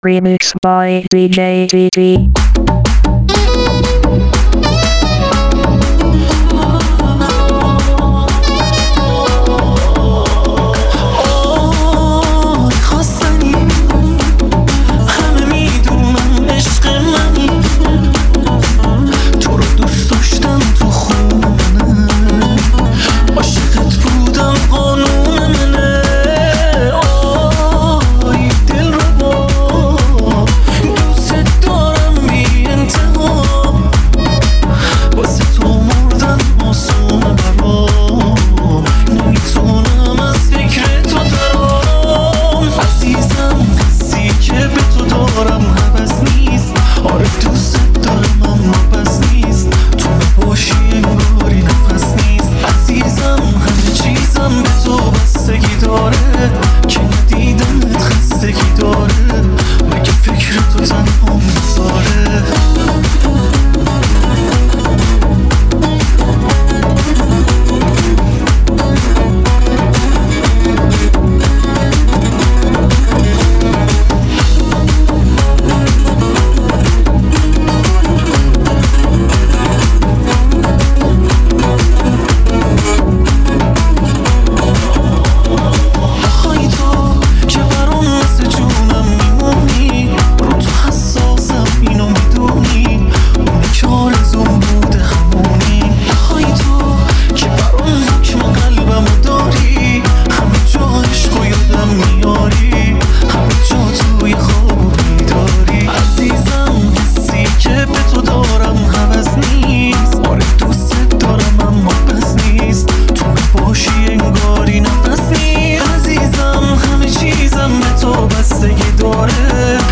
بیس دار